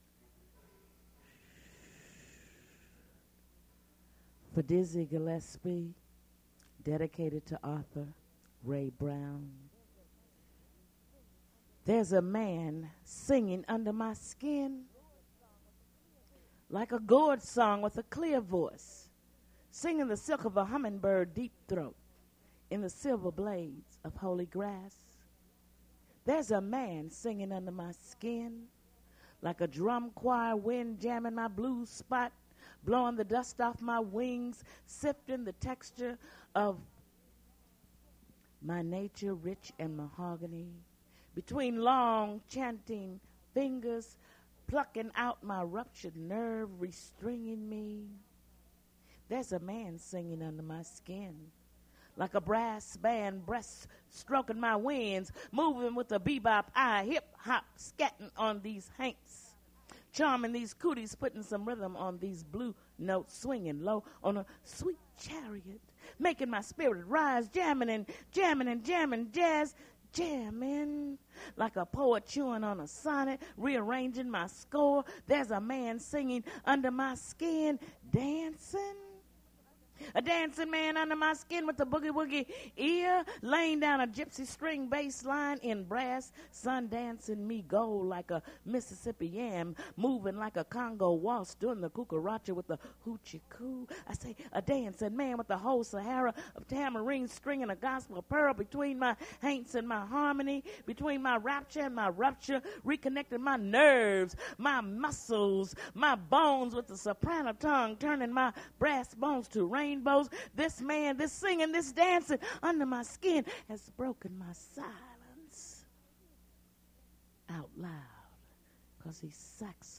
poetry reading at Duff's Restaurant
Source mp3 edited access file was created from unedited access file which was sourced from preservation WAV file that was generated from original audio cassette. Language English Identifier CASS.754 Series River Styx at Duff's River Styx Archive (MSS127), 1973-2001 Note no introduction recorded.